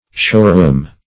Showroom \Show"room`\, n.